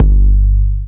low 808 kick.wav